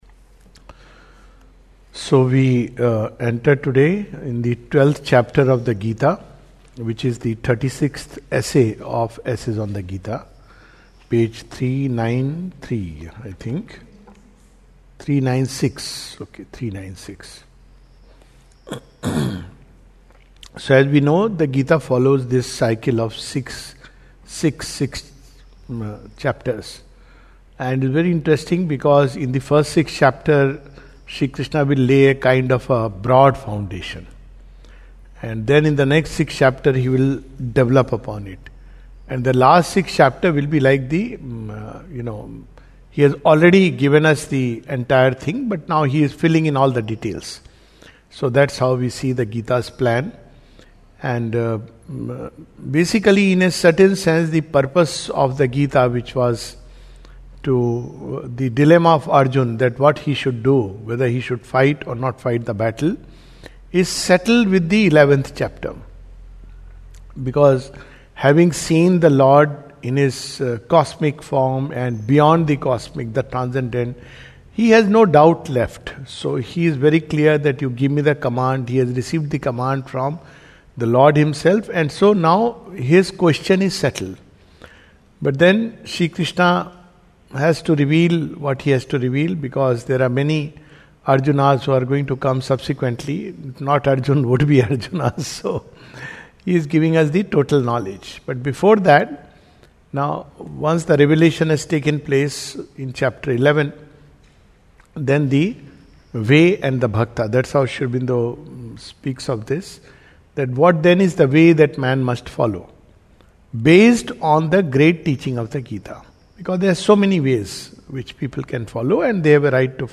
This is a summary of Chapter 12 of the Second Series of "Essays on the Gita" by Sri Aurobindo. Talk
recorded on September 24, 2025 at the Savitri Bhavan, Auroville.